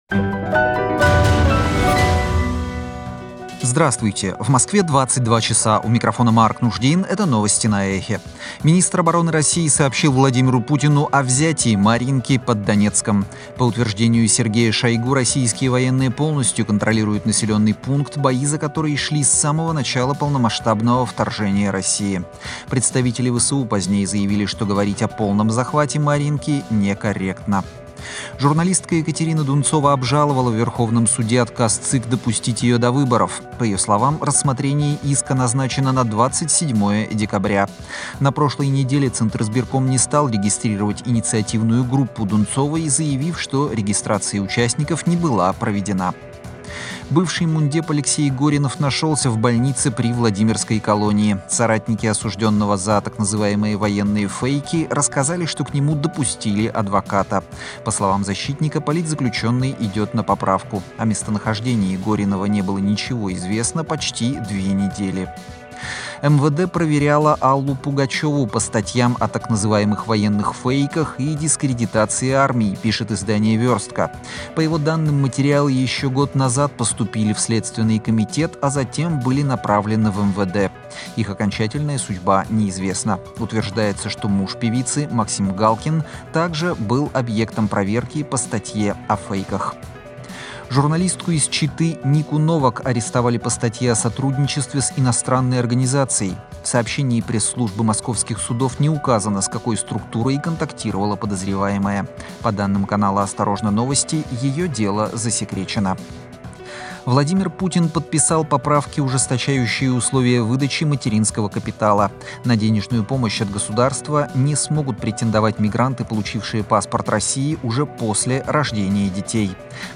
Новости 22:00